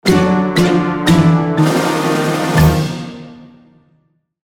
Game Over.mp3